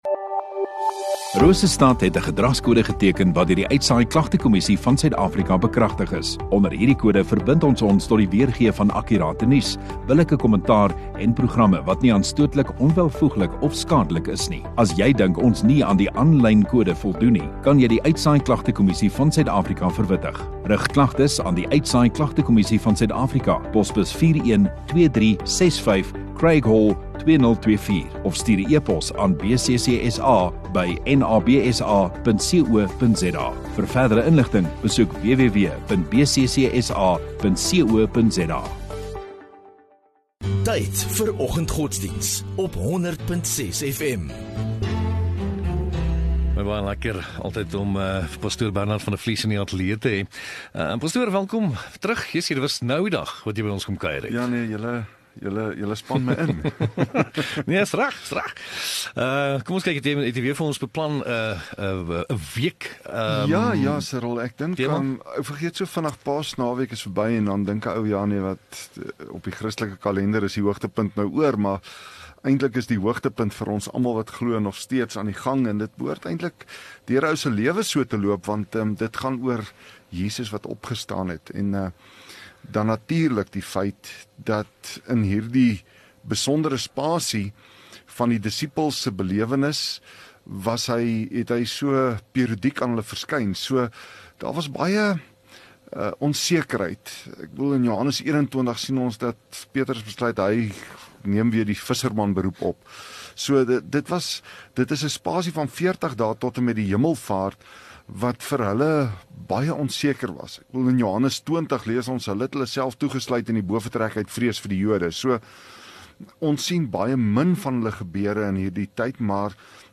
22 Apr Maandag Oggenddiens